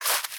Sfx_creature_penguin_foot_slow_walk_02.ogg